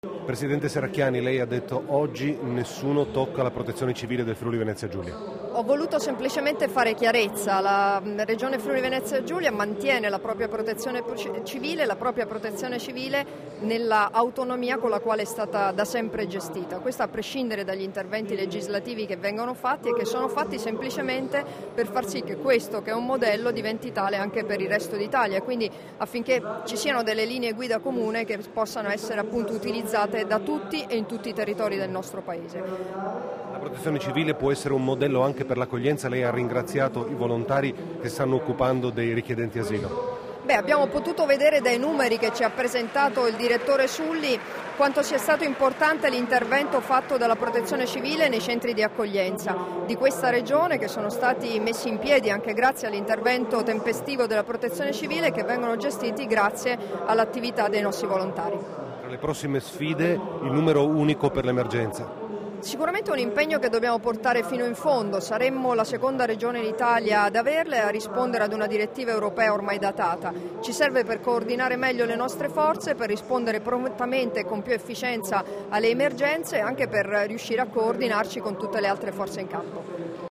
Dichiarazioni di Debora Serracchiani (Formato MP3) [1285KB]
alla XVII Giornata del Volontariato di Protezione civile regionale, rilasciate a Pordenone il 5 dicembre 2015
Lo ha ribadito la presidente della Regione Debora Serracchiani davanti all'imponente platea della XVII Giornata del Volontariato di Protezione civile regionale raccoltasi nel comprensorio fieristico di Pordenone.